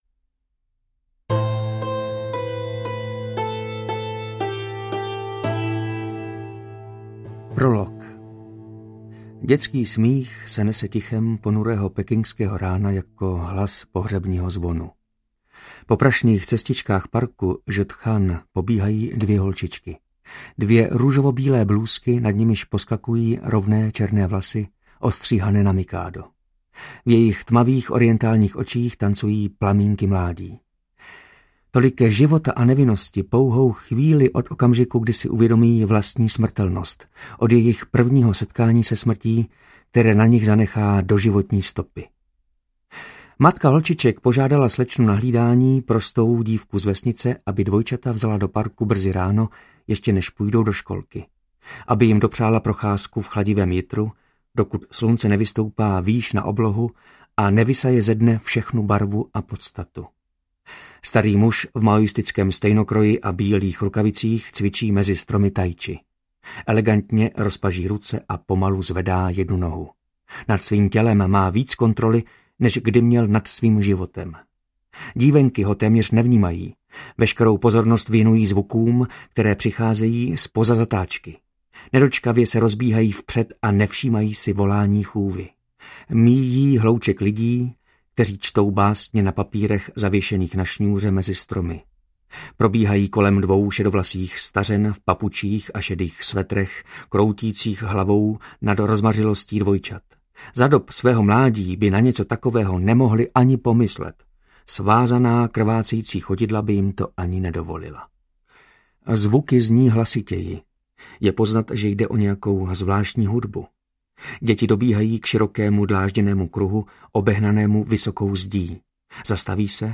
Ukázka z knihy
• InterpretJana Plodková, Martin Myšička